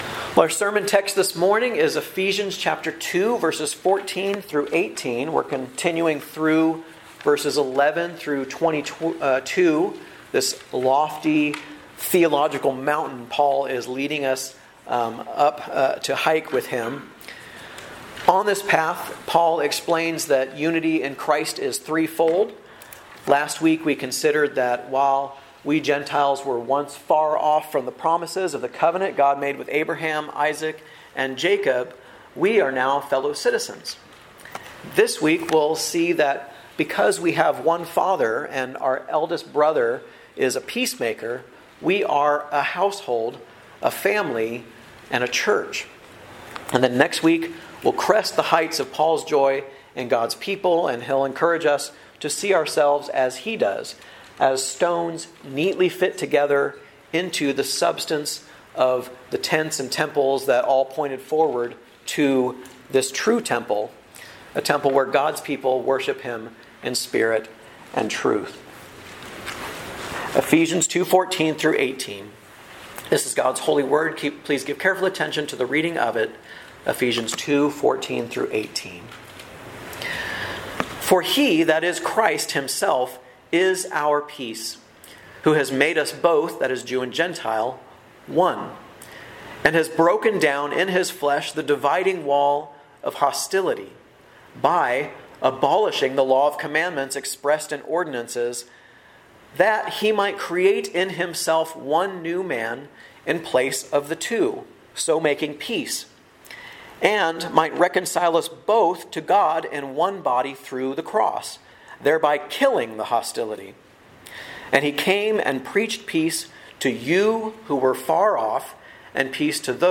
A message from the series "Guest Preacher." 1.